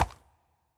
Minecraft Version Minecraft Version snapshot Latest Release | Latest Snapshot snapshot / assets / minecraft / sounds / mob / horse / skeleton / water / soft4.ogg Compare With Compare With Latest Release | Latest Snapshot